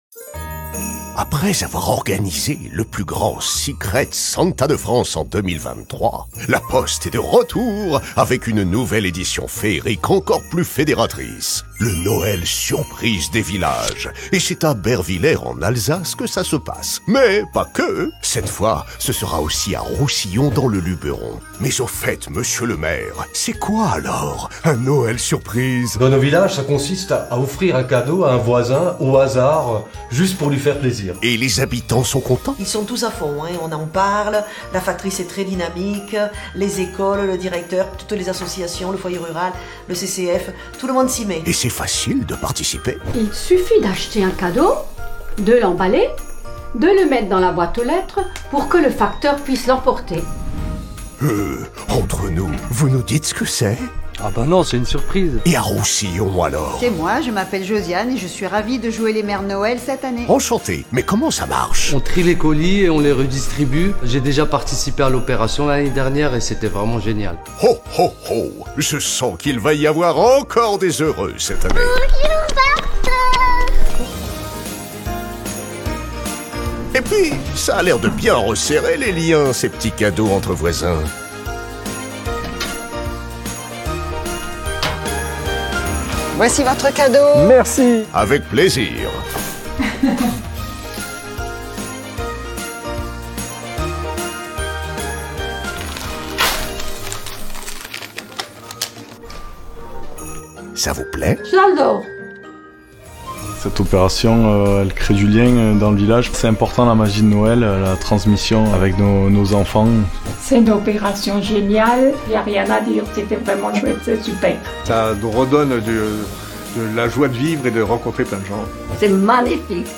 Voix grave et chaleureuse.
Télécharger l'audio Père Noël de la Poste Père Noël enthousiaste. Read More très grave chaleureux drôle père noël personnage Vidéo Instagram pour La Poste. Enregistrée chez Maw. La Poste organise tous les ans le Noël des voisins.
Je suis allé cherché dans mes graves et j’ai voulu apporter à ma voix un maximum de rondeur et de chaleur. Ce père Noël est joyeux, rigolo et un peu enfantin.